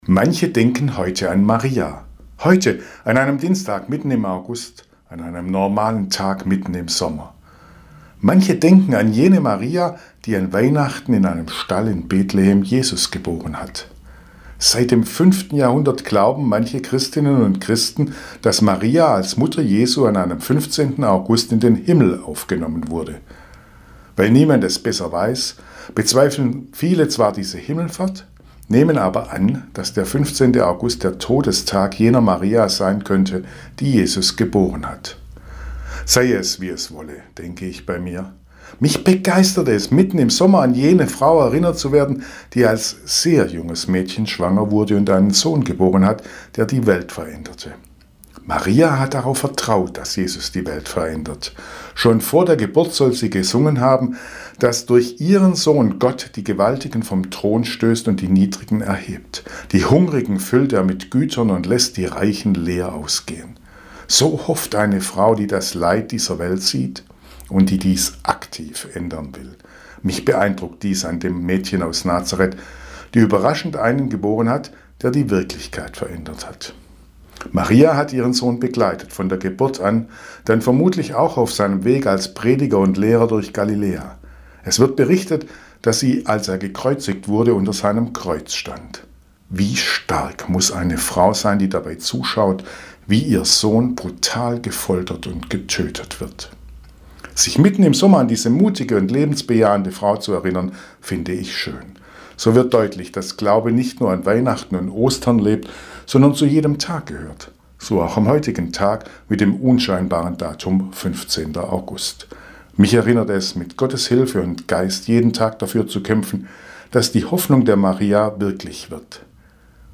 Radioandacht vom 15. August